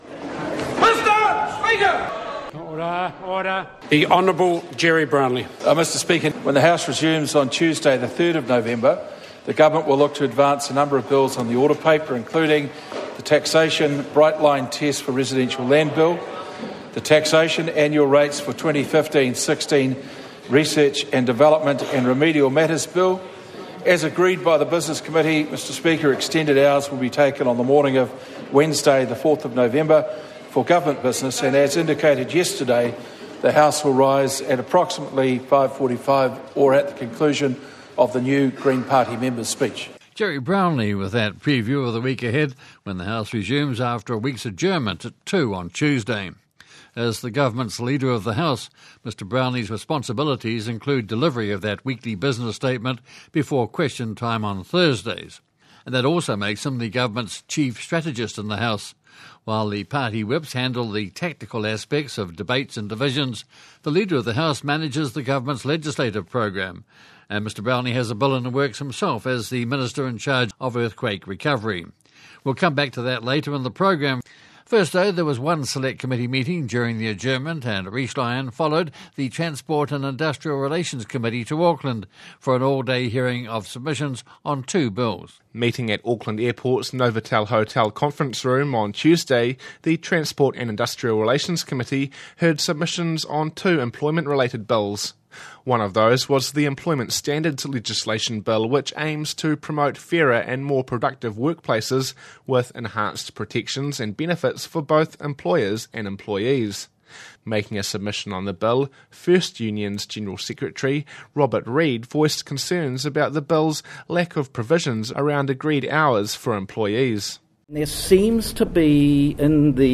featuring a round-up of MPs’ congratulatory speeches